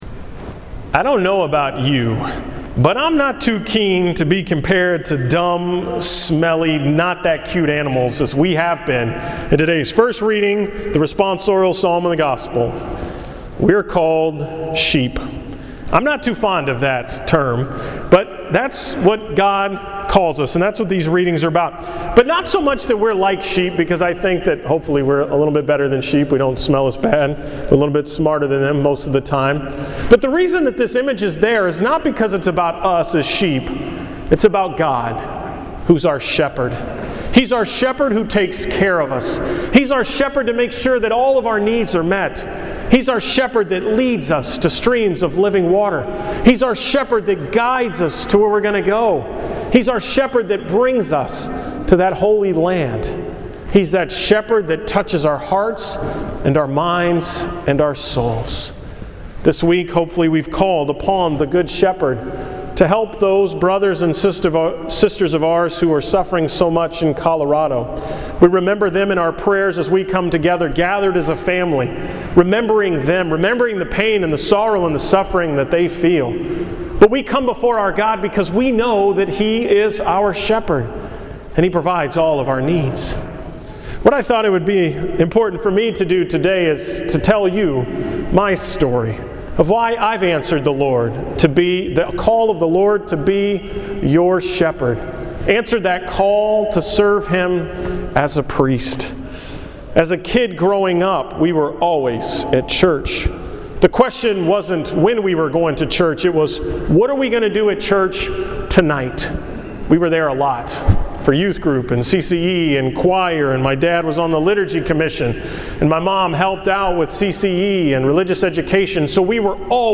July 22, 2012 homily
From the 9 am Mass at St. Mary’s.